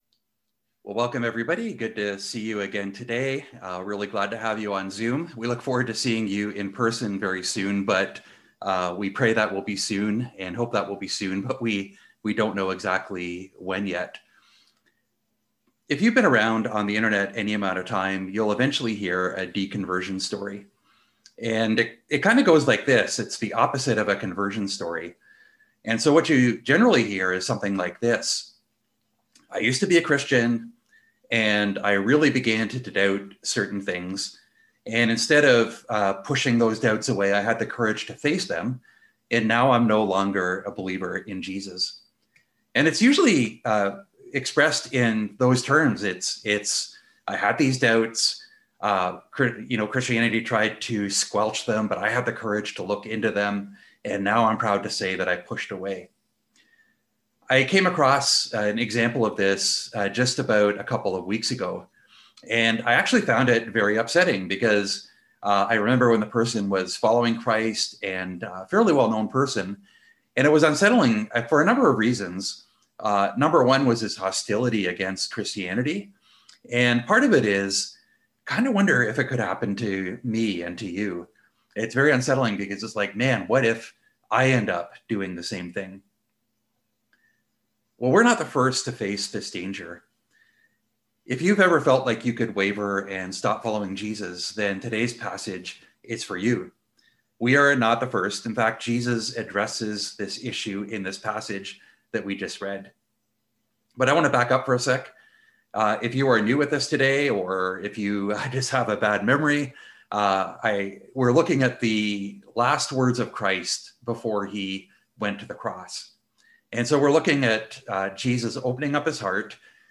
A sermon from John 15:26-16:15